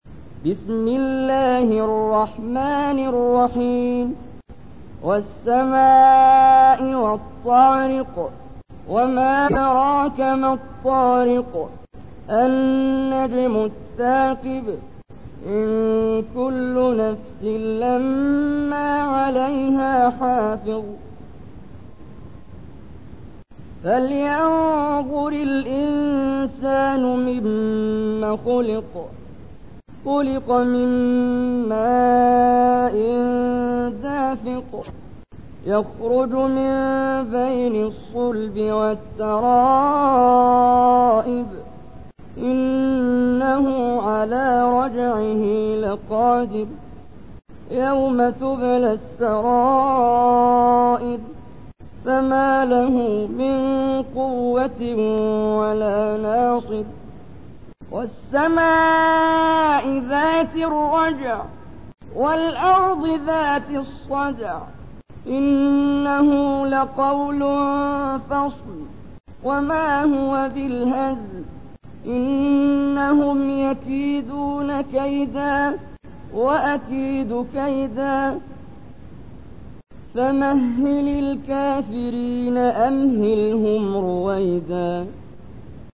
تحميل : 86. سورة الطارق / القارئ محمد جبريل / القرآن الكريم / موقع يا حسين